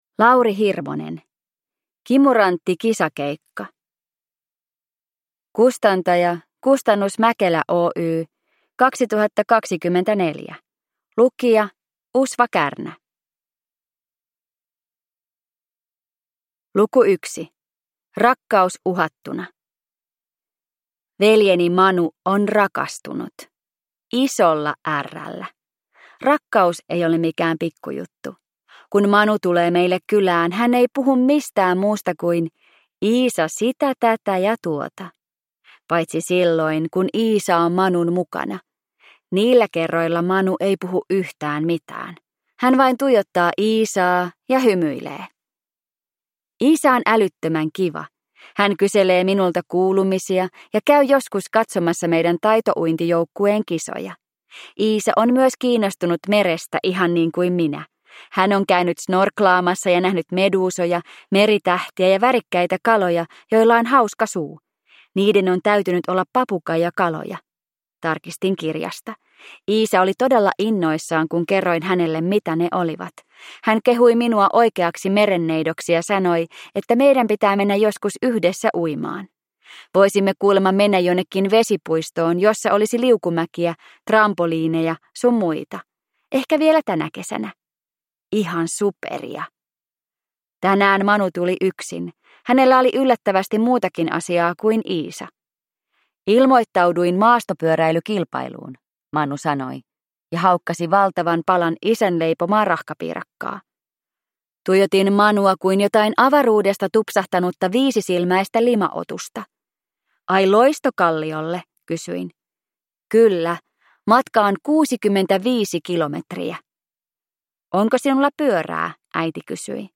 Kimurantti kisakeikka (ljudbok) av Lauri Hirvonen